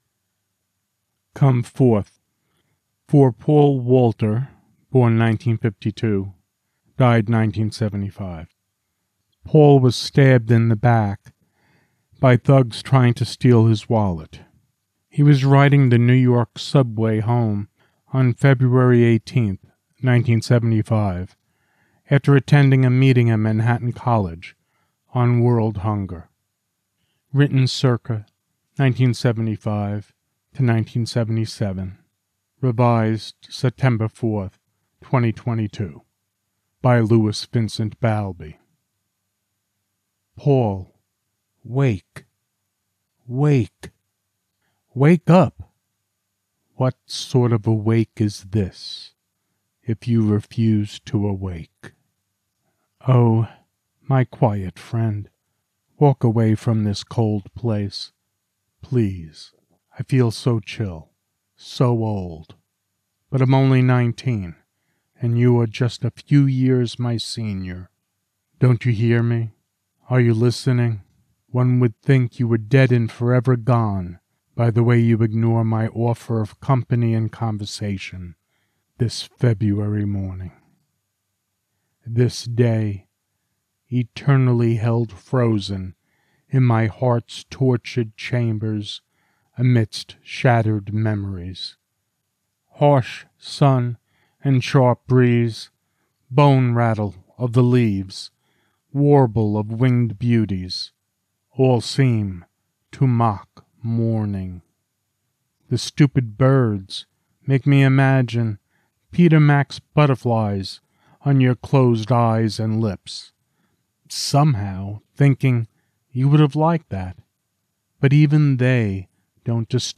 Come Forth Poem